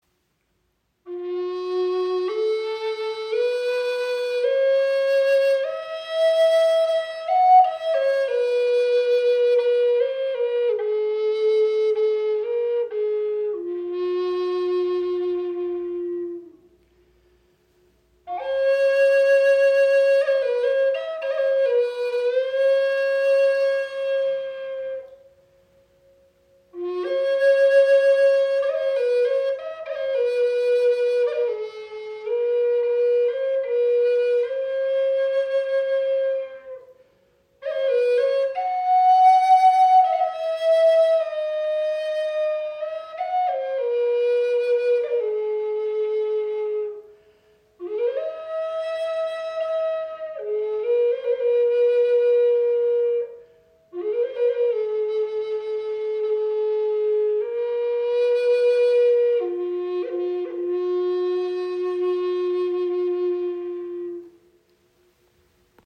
Gebetsflöte in F# - 432 Hz
• Bass Gebetsflöte in tiefem D mit 432 Hz
• 53 cm lang, 6 Grifflöcher